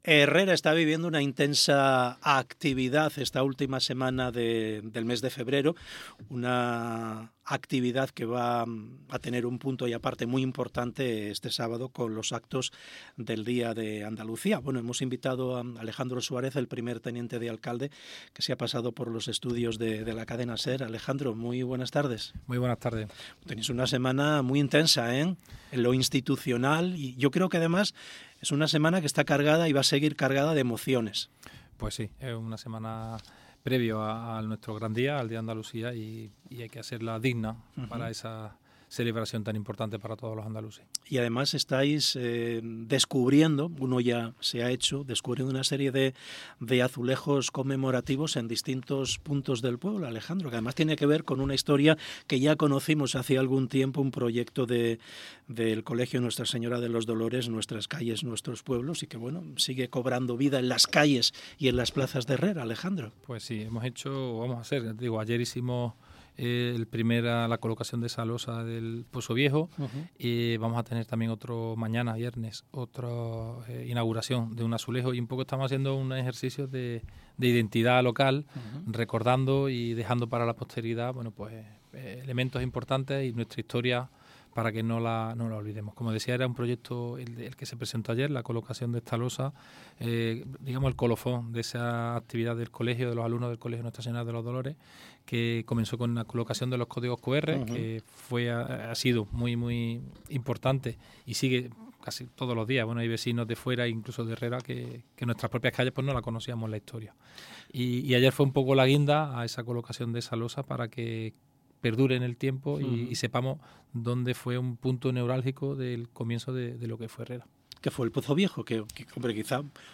ENTRREVISTA